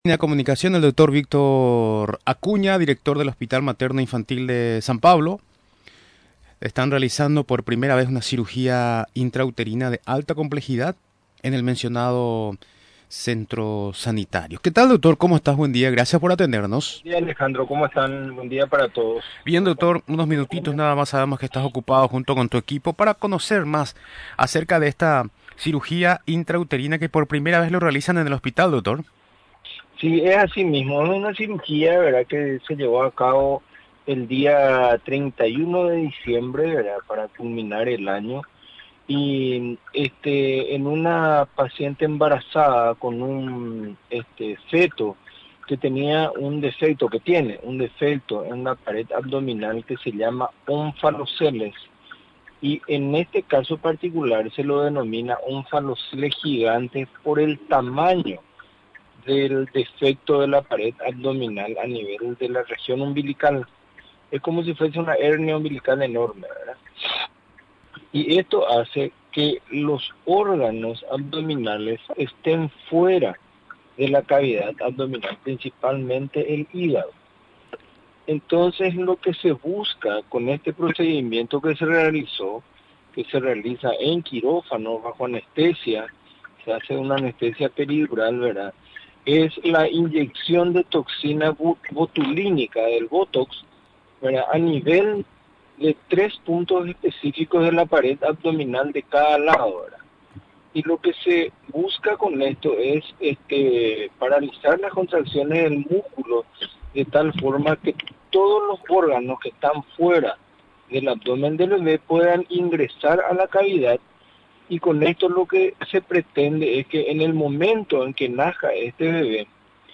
Durante la entrevista en Radio Nacional del Paraguay, explicó que la paciente, se trata de una embarazada de 35 semanas, Añadió que se procedió a la cirugía porque el feto presentaba un onfalocele. Finalmente, refirió que es una malformación congénita de la pared abdominal.